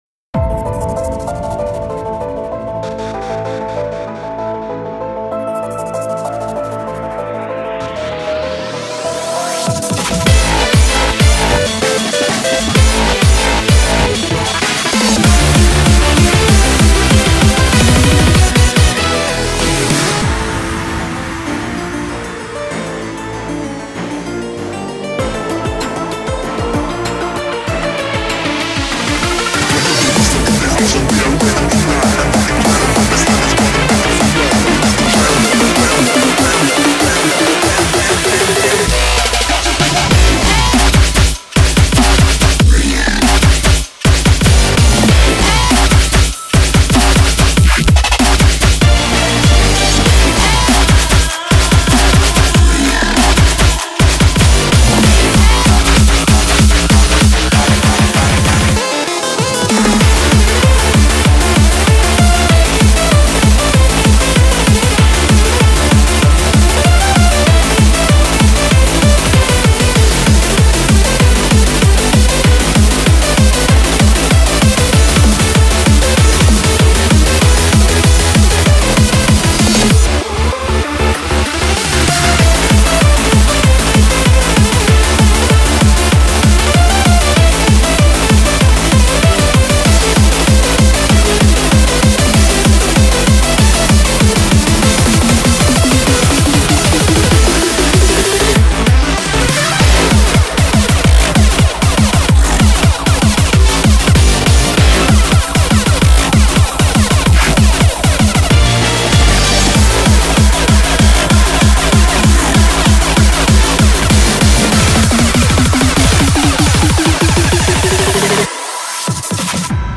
BPM96-193
Audio QualityPerfect (Low Quality)